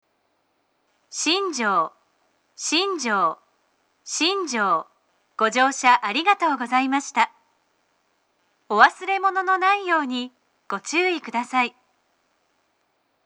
2016年末頃に放送装置装置を更新し、自動放送のアナウンサーが変更され、音声にノイズが被るようになりました。
到着放送